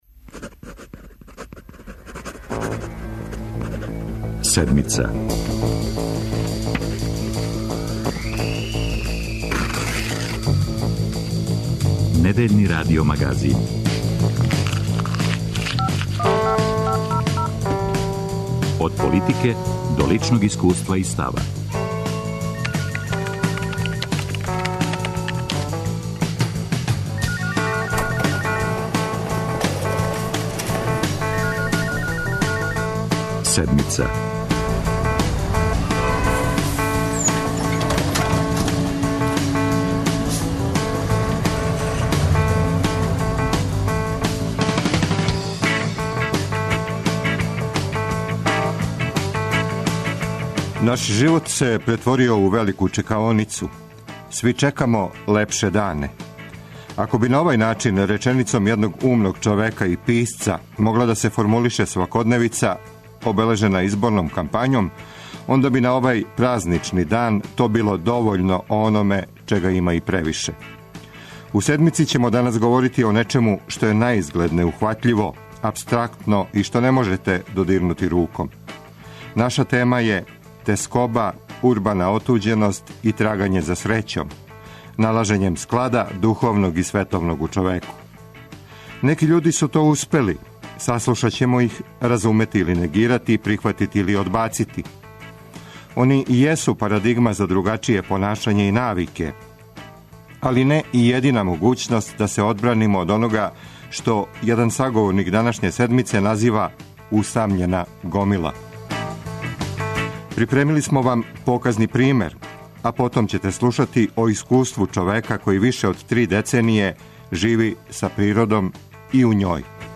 Говоре: социолог културе